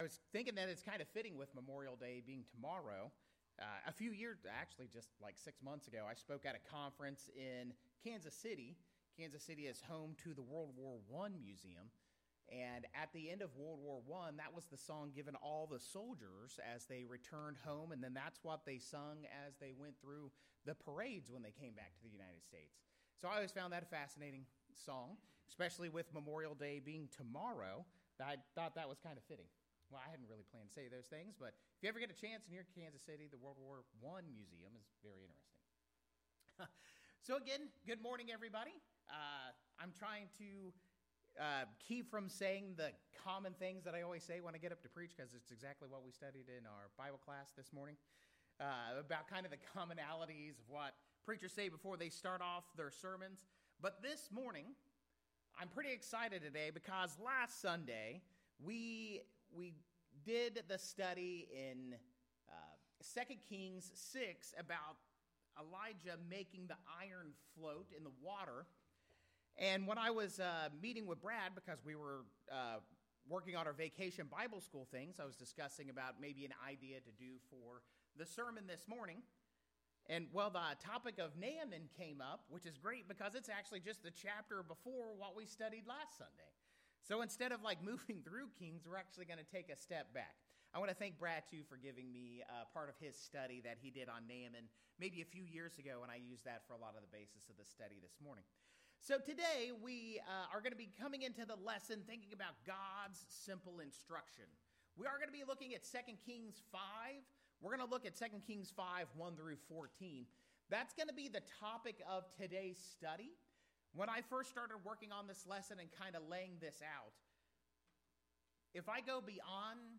The sermon aimed to show that God’s instructions are simple but must be followed exactly.